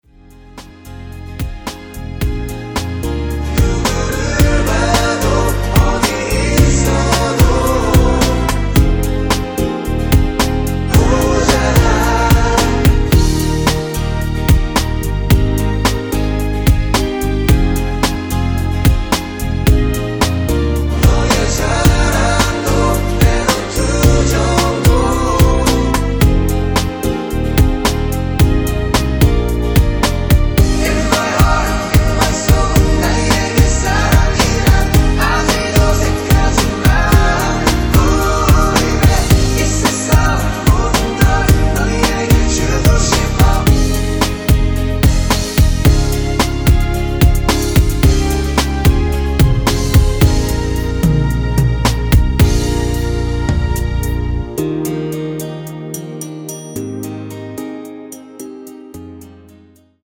원키 코러스 포함된 MR입니다.(미리듣기 확인)
Db
앞부분30초, 뒷부분30초씩 편집해서 올려 드리고 있습니다.